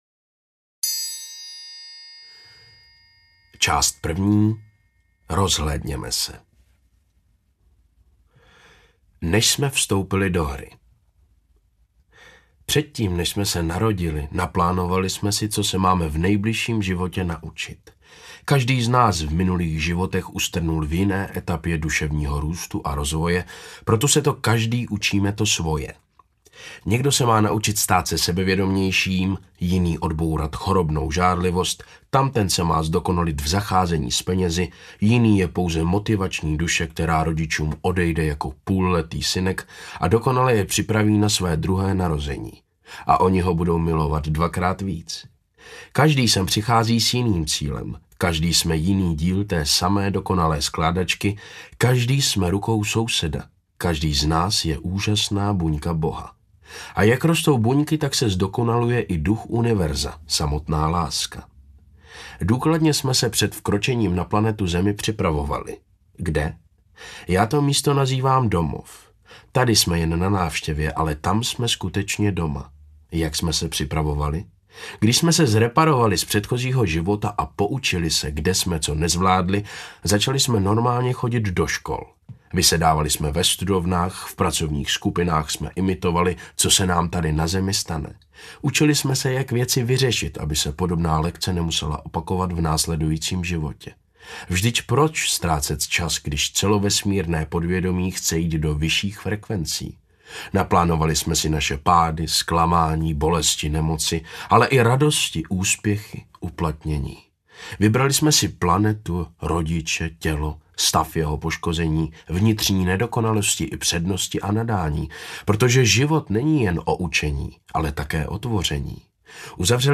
Audio knihaSlabikář štěstí 1 - Návrat k sobě
Ukázka z knihy